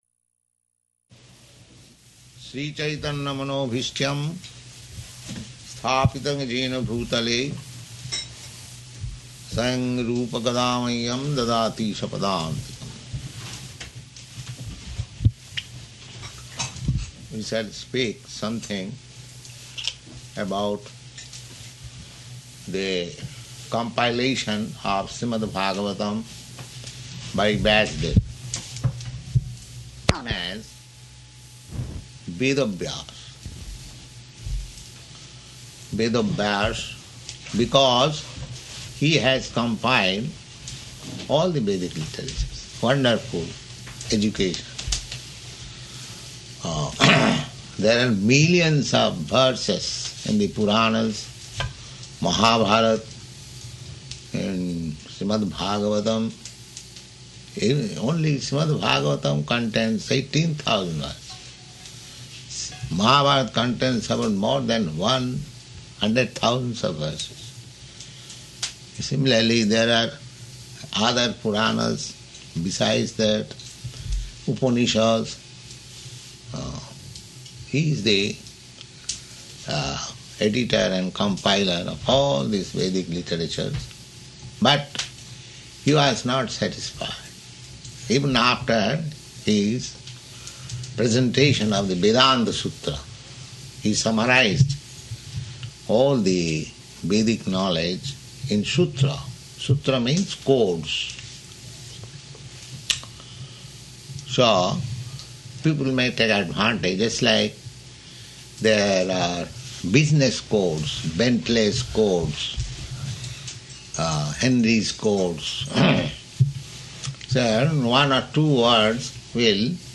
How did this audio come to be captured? Location: New Vrindavan